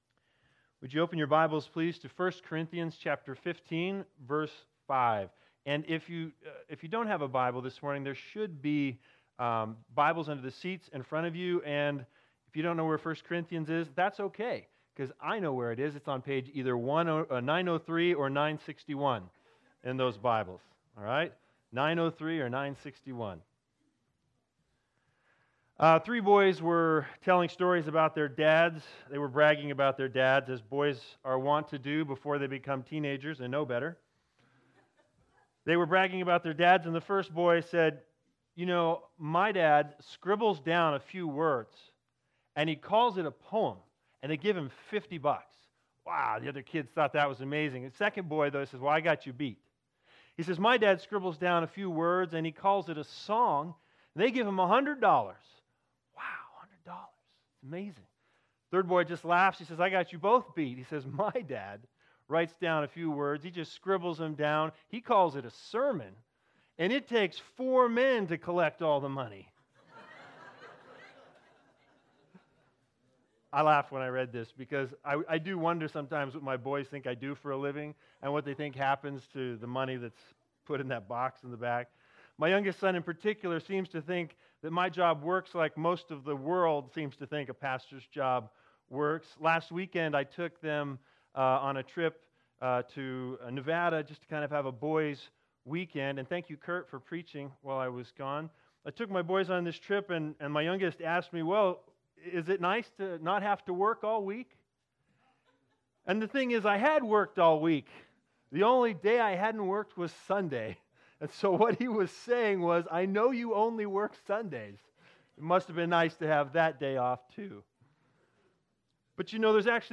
11/17/2019 A Reasonable Faith Preacher